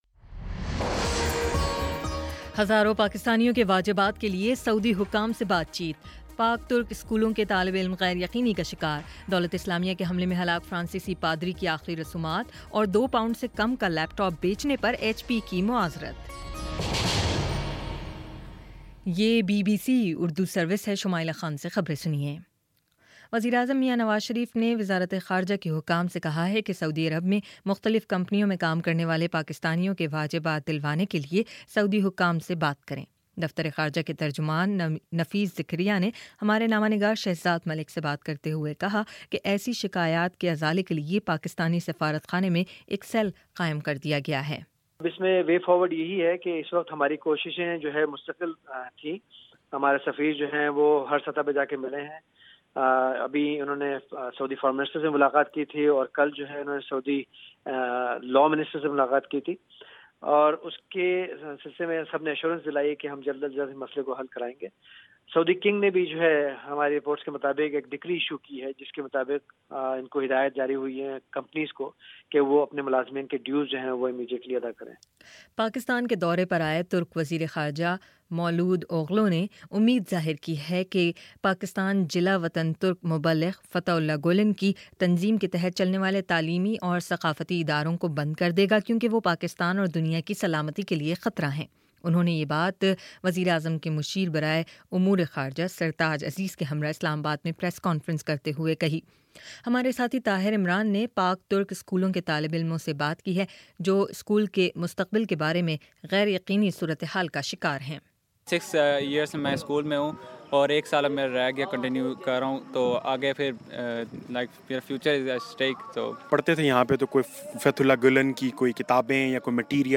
اگست 02 : شام سات بجے کا نیوز بُلیٹن